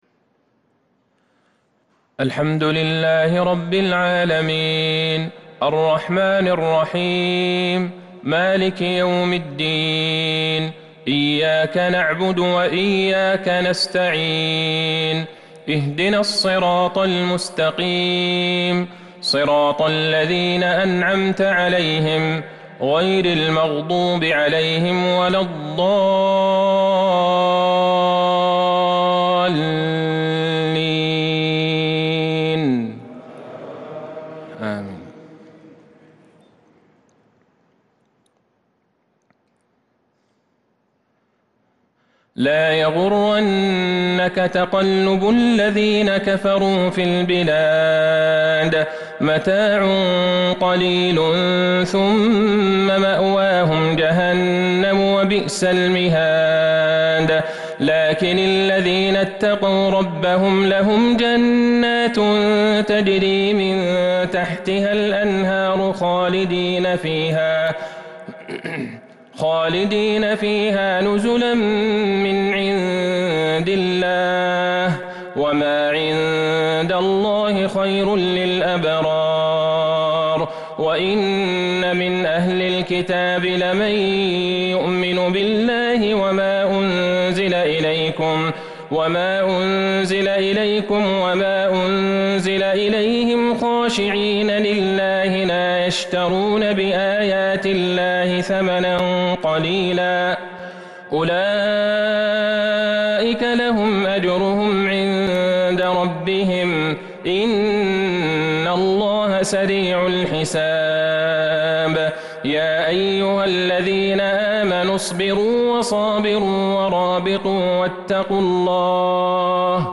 صلاة المغرب للقارئ عبدالله البعيجان 7 ربيع الأول 1443 هـ